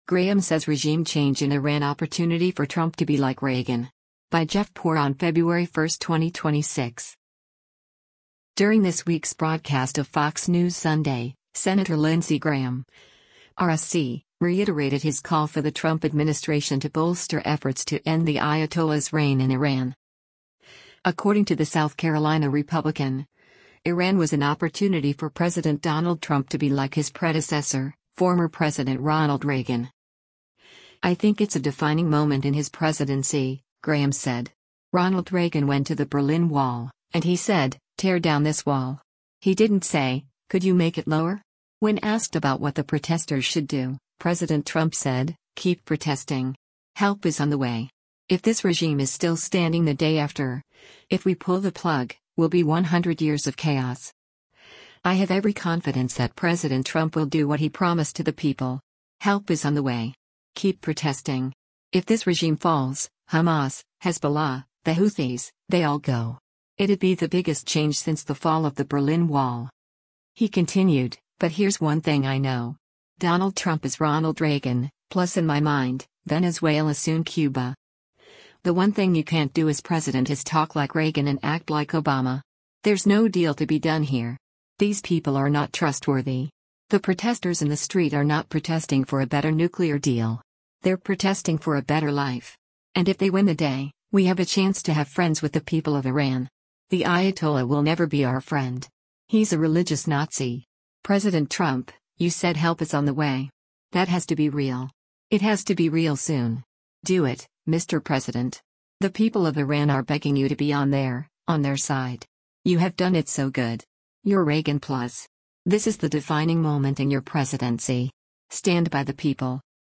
During this week’s broadcast of “Fox News Sunday,” Sen. Lindsey Graham (R-SC) reiterated his call for the Trump administration to bolster efforts to end the Ayatollah’s reign in Iran.